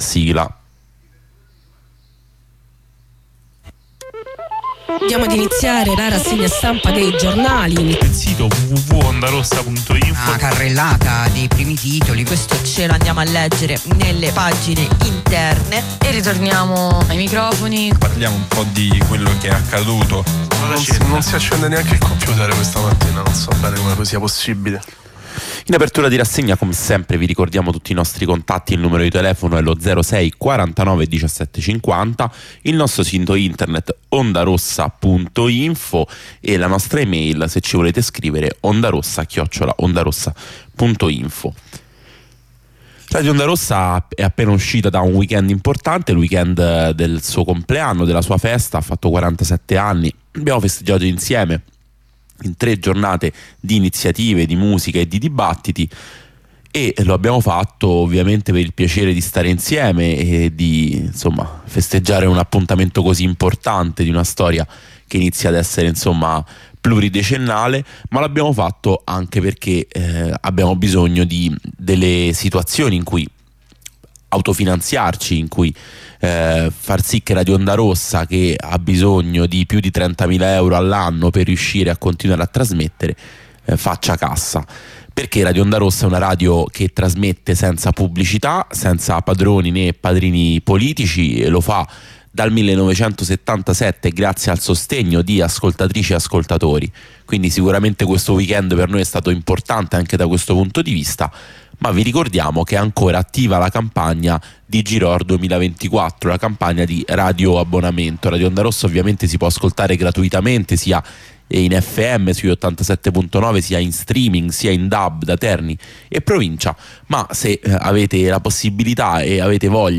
Lettura e commento dei quotidiani.